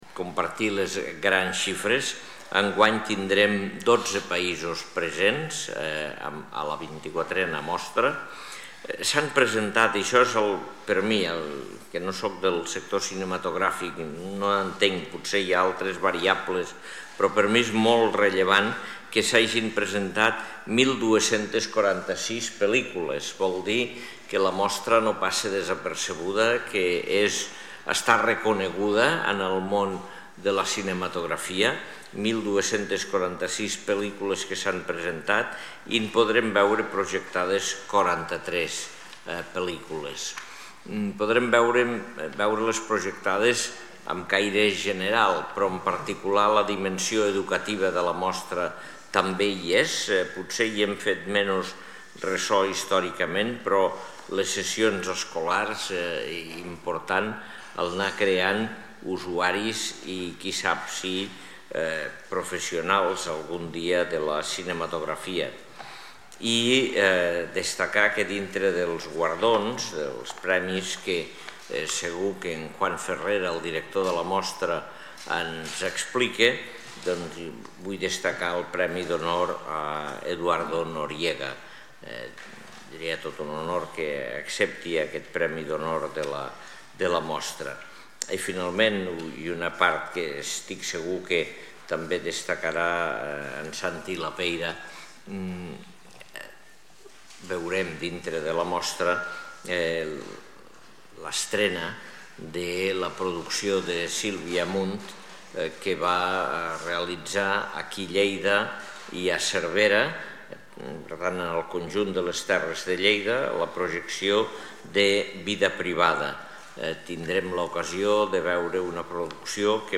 Tall de veu de l'alcalde, Àngel Ros, sobre la presentació de la 24a Mostra de Cinema Llatinoamericà de Catalunya, que té lloc a Lleida del 20 al 27 d'abril